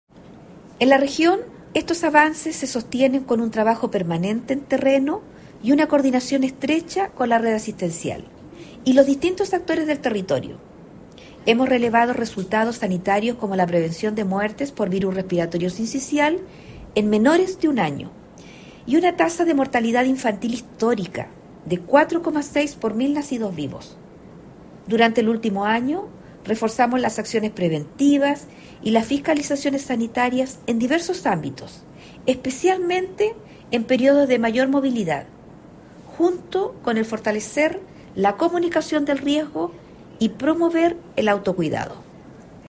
Al respecto, la Seremi de Salud de Atacama, Jéssica Rojas Gahona, señaló que “en la región, estos avances se sostienen con trabajo permanente en terreno y una coordinación estrecha con la red asistencial y los distintos actores del territorio.